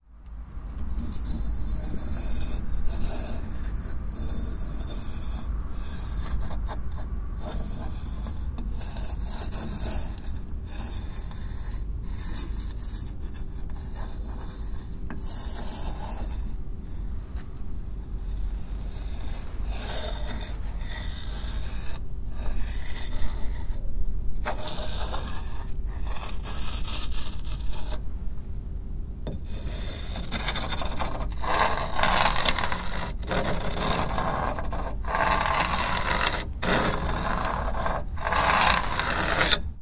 火车行驶声音
描述：火车行驶的声音
Tag: 火车 行驶 铁轨